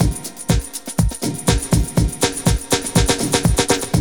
BBEAT 1+FILL.wav